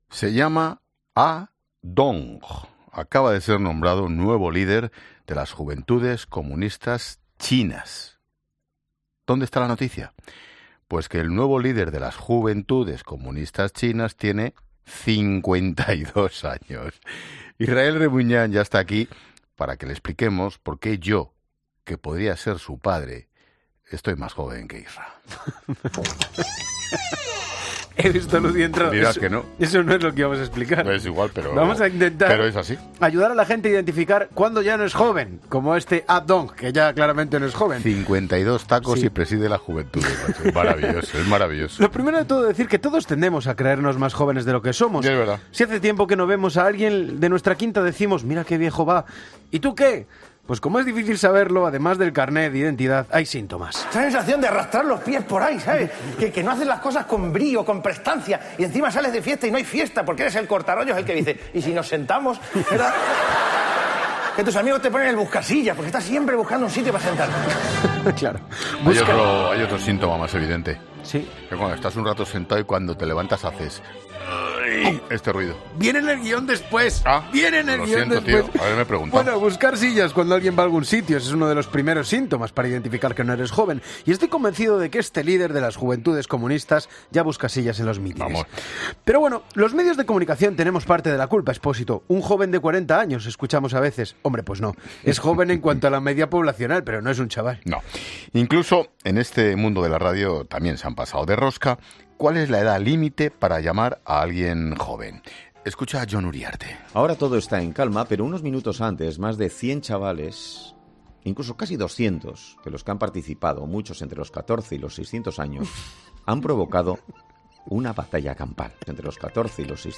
Un chiste del humorista Luis Lara Ramos, conocido artísticamente como Comandante Lara, destaba en La Linterna las risas del director del programa tras escucharlo en directo